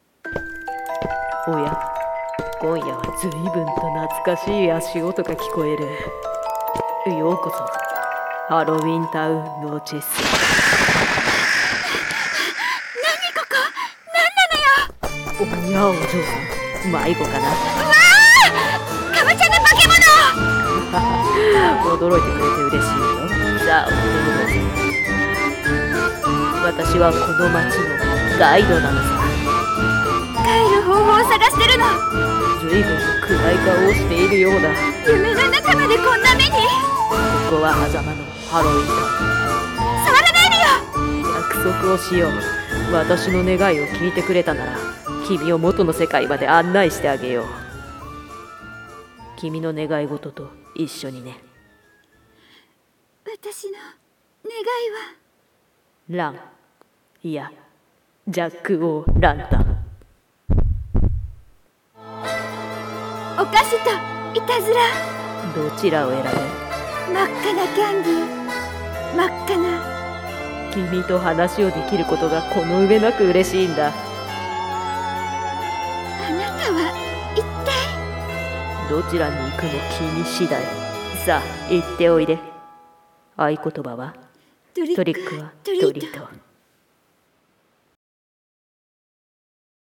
ハロウィン🎃声劇】The Noches Town